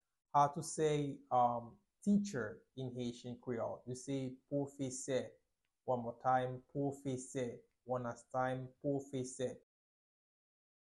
Pronunciation:
21.How-to-say-Teacher-in-haitian-creole-–-Pwofese-pronunciation.mp3